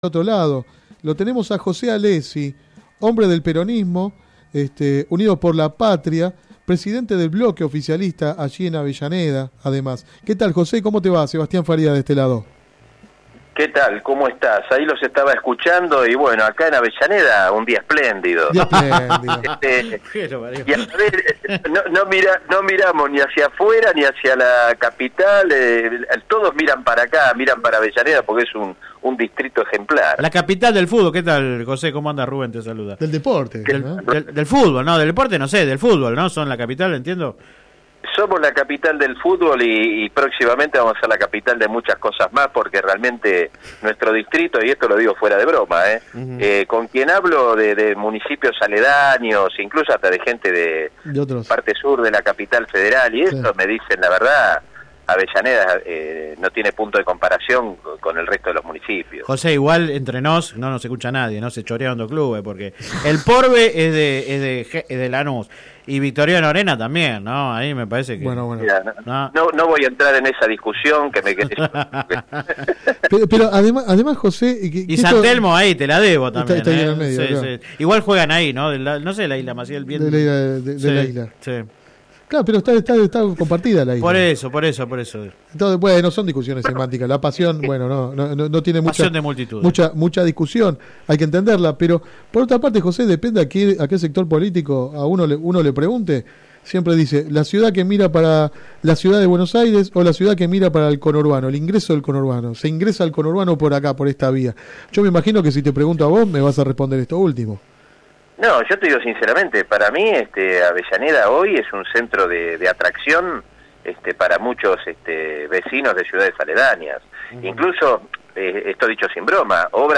En diálogo con el programa radial Sin Retorno (lunes a viernes de 10 a 13 por GPS El Camino FM 90 .7 y AM 1260) habló de la renovación política, criticó a la oposición y ponderó la candidatura presidencial de Sergio Massa.